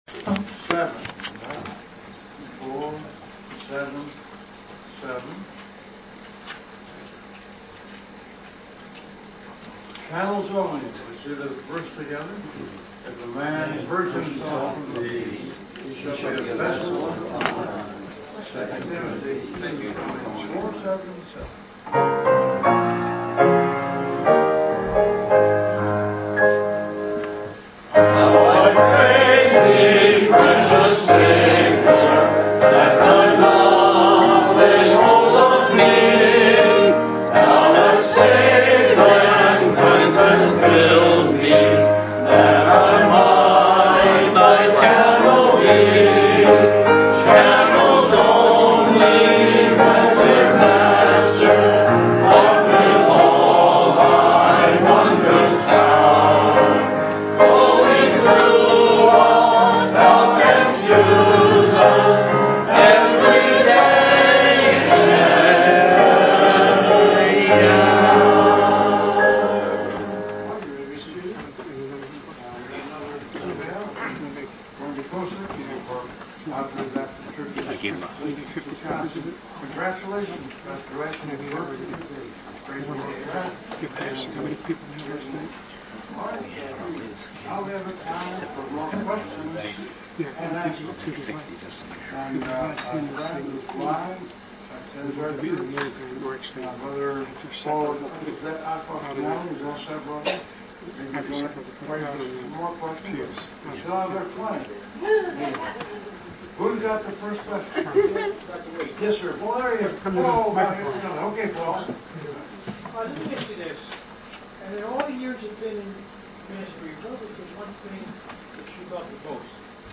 DBS 2018 – SESSION 14 – Q&A #2